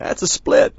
b_thatsasplit.wav